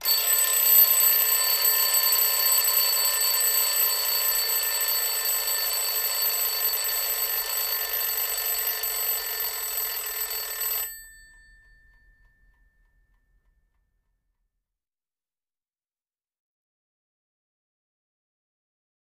Clock Alarm Old 1; Rings With Bell